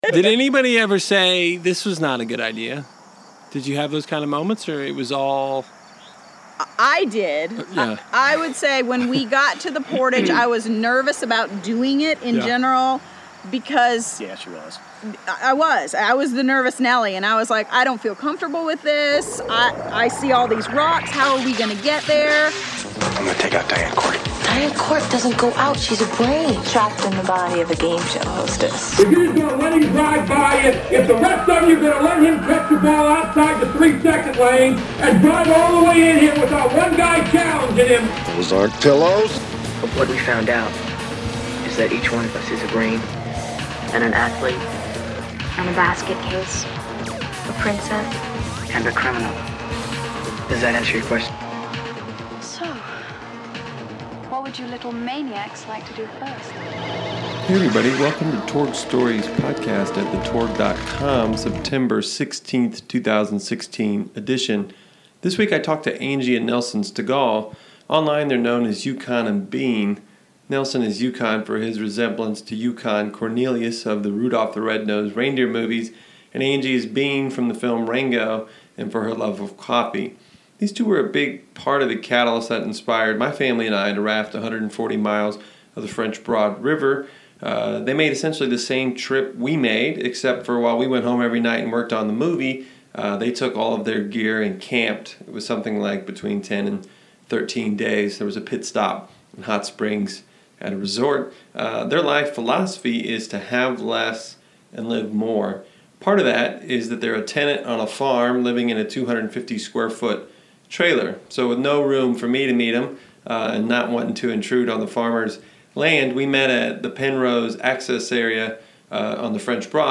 You’ll hear the occasional car drive by and several times a small airplane.